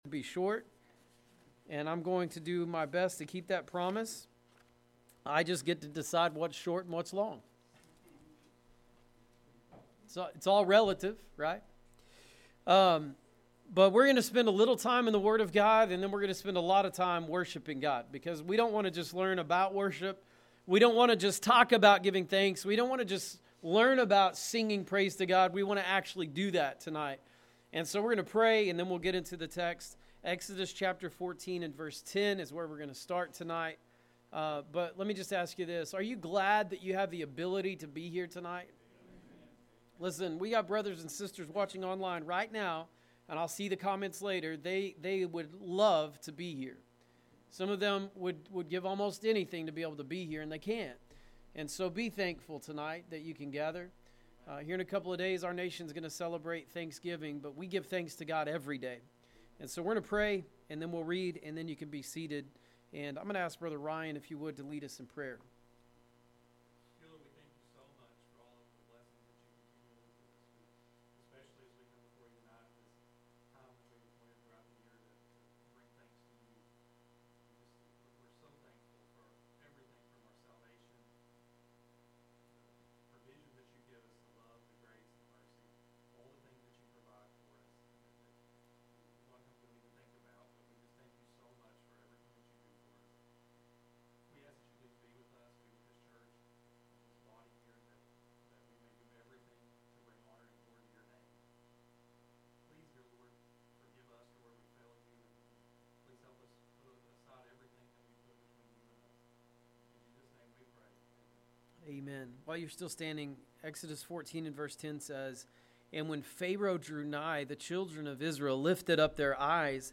Thanksgiving Night Of Worship